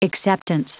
Prononciation du mot acceptance en anglais (fichier audio)
Prononciation du mot : acceptance